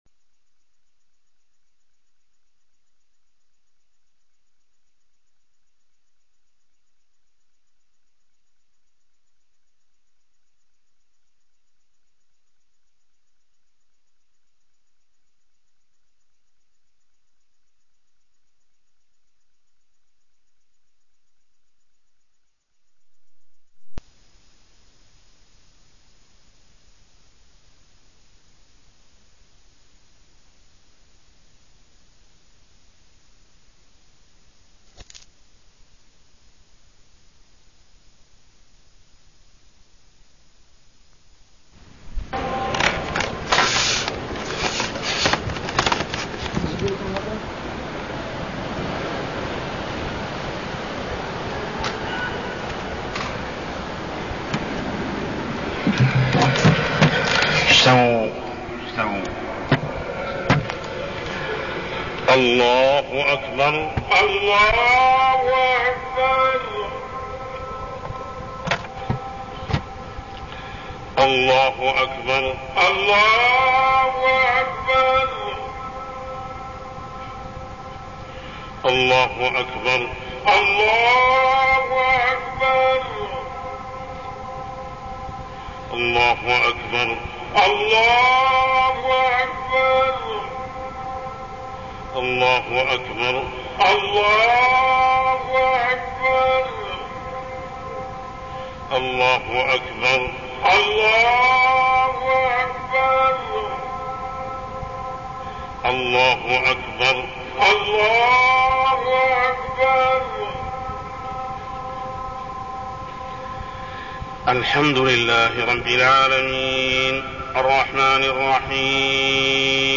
تاريخ النشر ٢١ جمادى الأولى ١٤١٣ هـ المكان: المسجد الحرام الشيخ: محمد بن عبد الله السبيل محمد بن عبد الله السبيل أسباب القحط